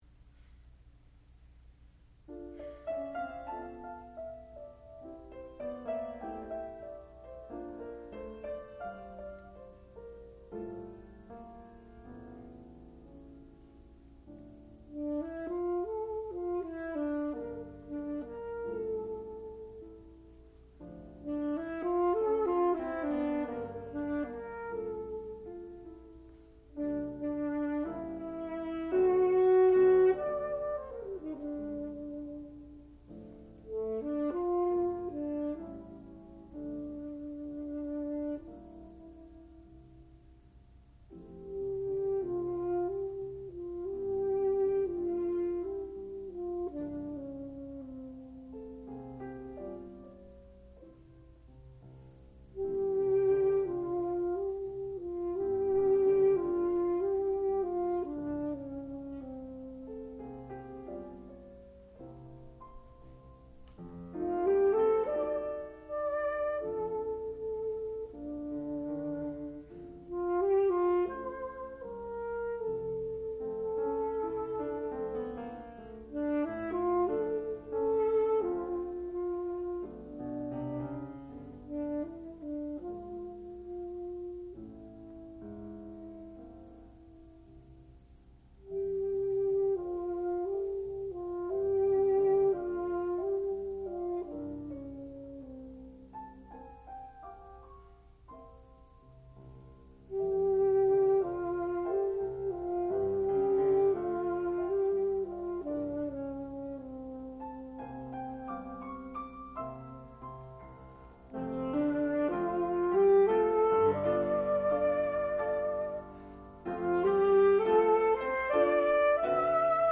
sound recording-musical
classical music
saxophone
piano